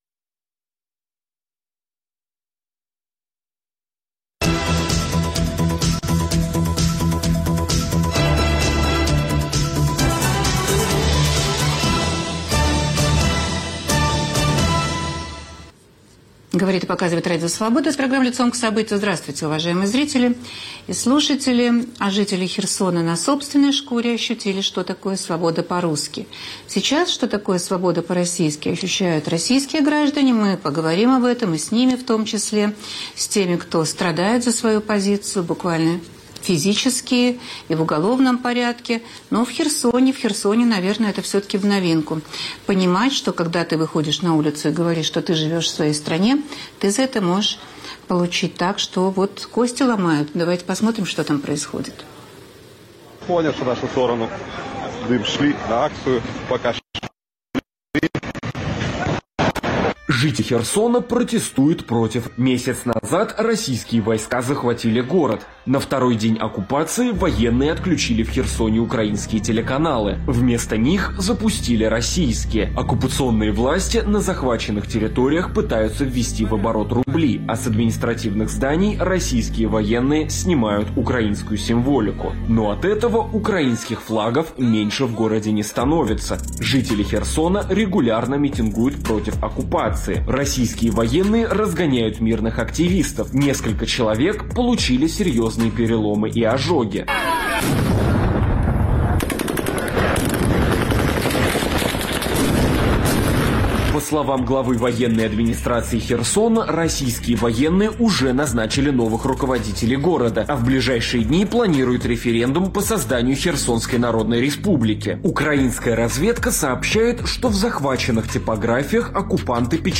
Откуда они берут силы и мужество, чтобы противостоять попыткам заткнуть им рот? В программе участвуют: депутат от КПРФ Семилукского района Нина Беляева, глава Красносельского муниципального совета Елена Котеночкина